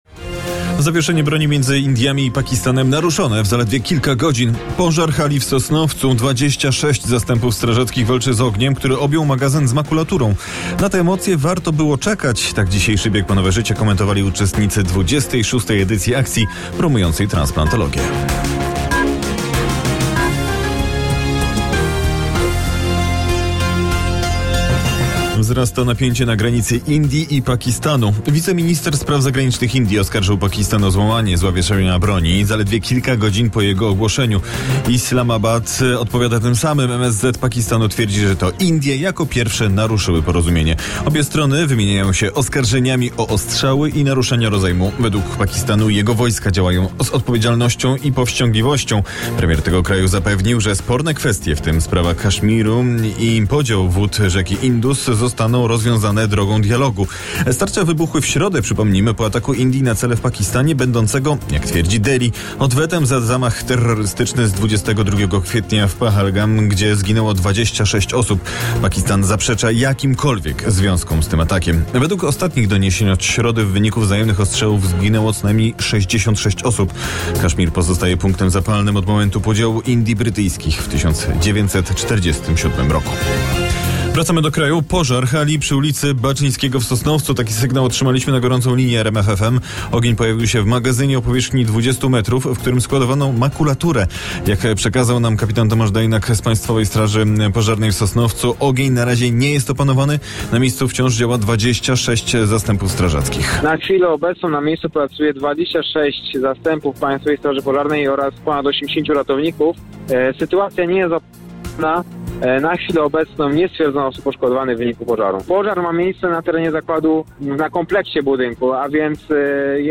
Najświeższe wiadomości z kraju i świata przygotowywane przez dziennikarzy i korespondentów RMF FM. Polityka, społeczeństwo, sport, kultura, ekonomia i nauka.